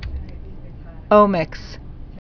(ōmĭks, ŏmĭks)